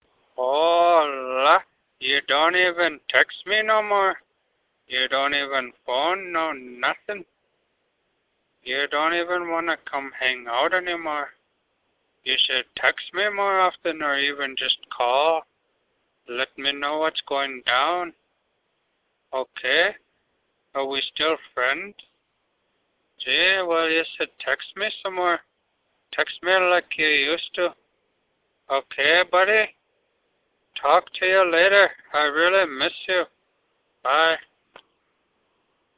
Trippy cats cellphone sounds " Trippy Cats Income Message
描述：Trippy猫收入消息声音由我的4只猫的喵喵声制成。
标签： 收入 迷幻 短信 电话
声道立体声